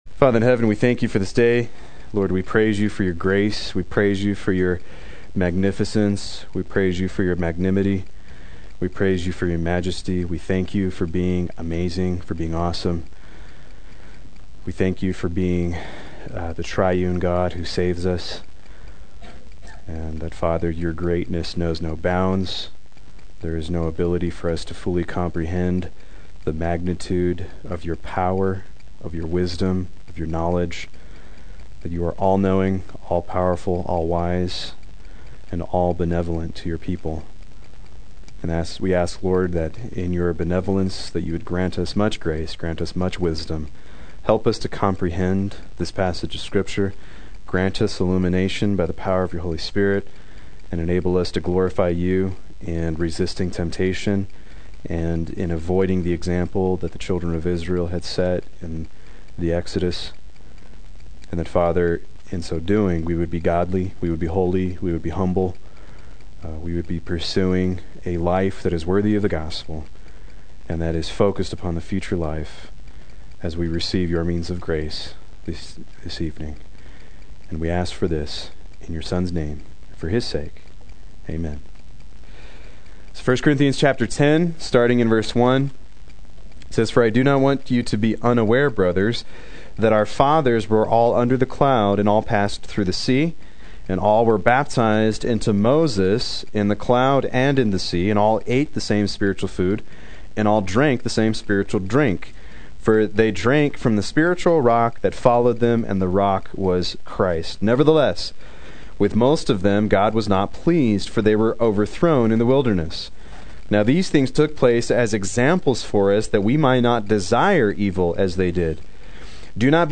Proclaim Youth Ministry - 07/08/16
Play Sermon Get HCF Teaching Automatically.